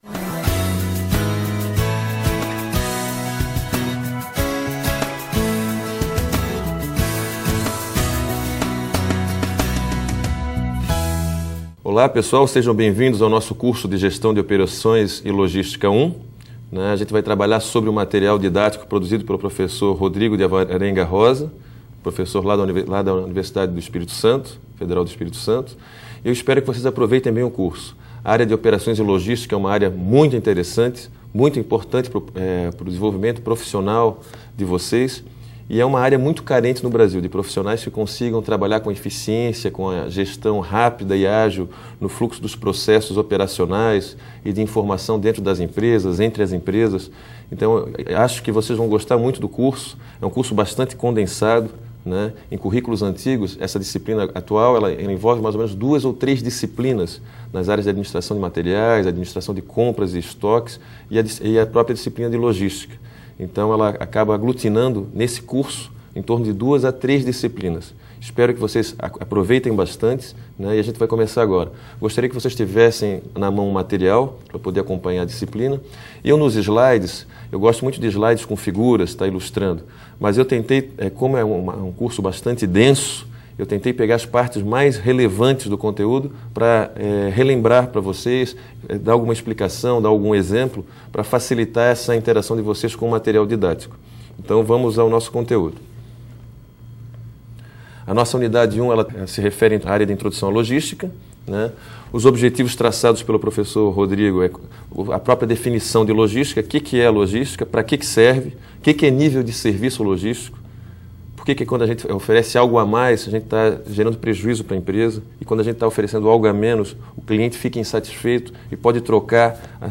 aula_1.mp3